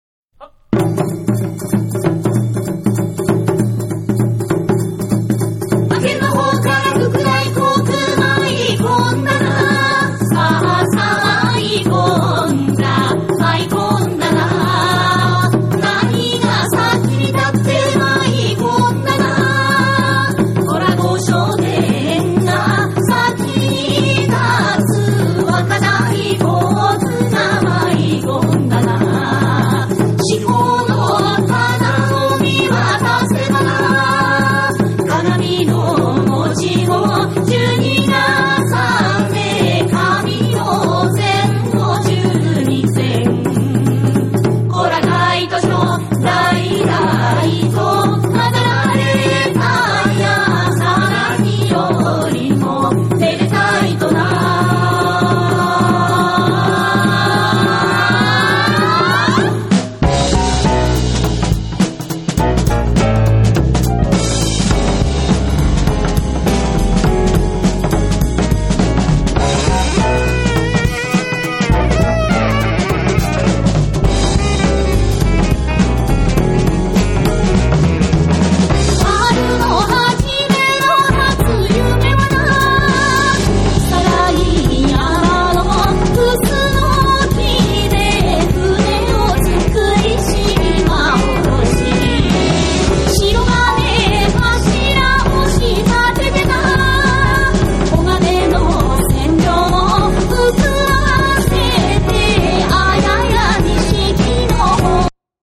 JAPANESE